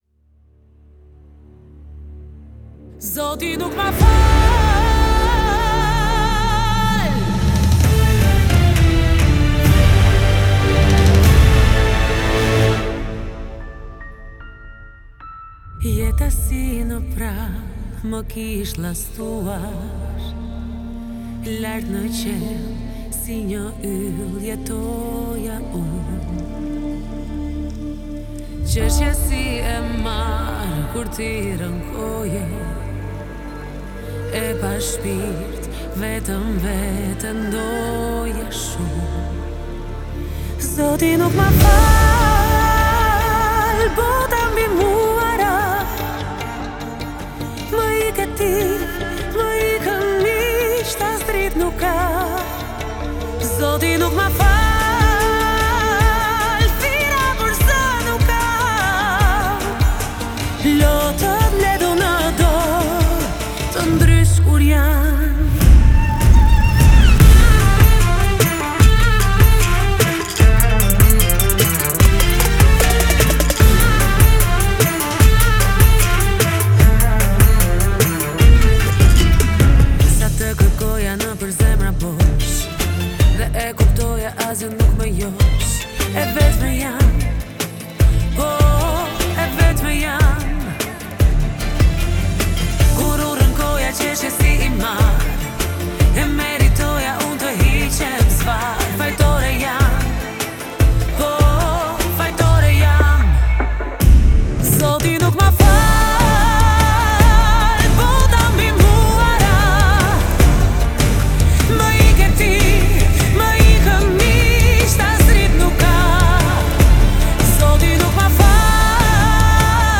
мощная поп-песня